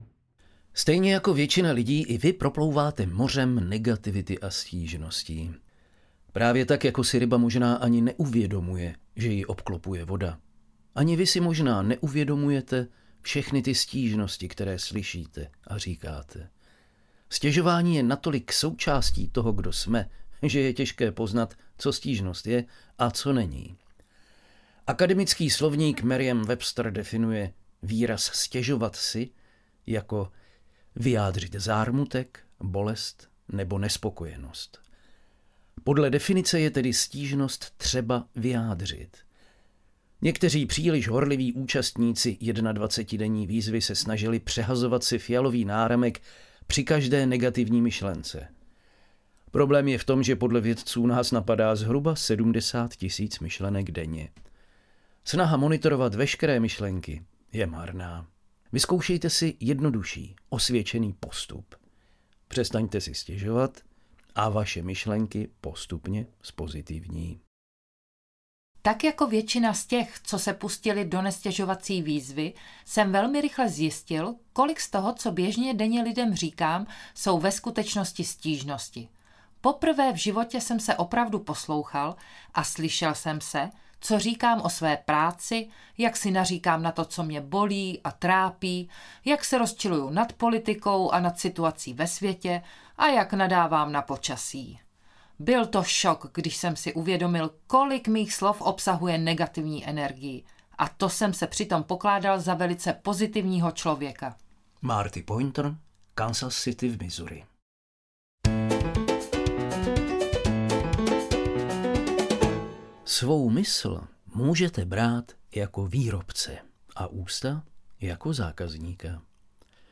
Svět bez stížností audiokniha
Ukázka z knihy